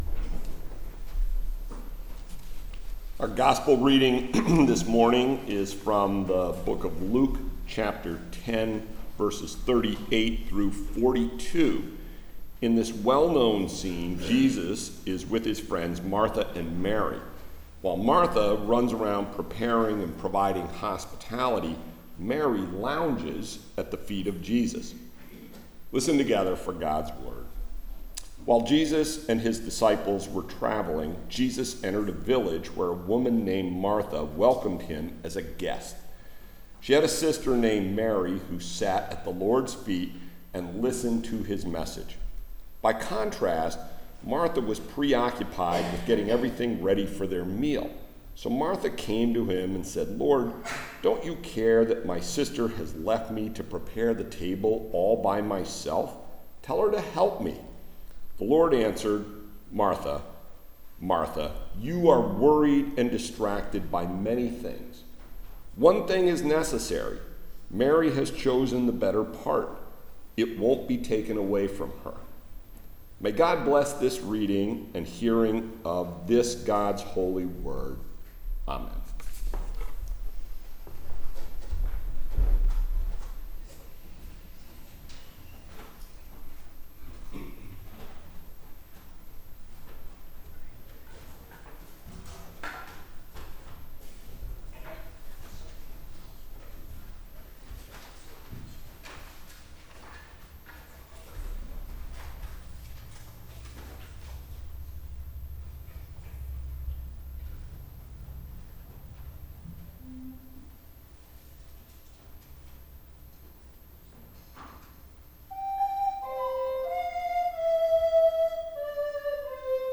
Message Delivered at: The United Church of Underhill (UCC and UMC)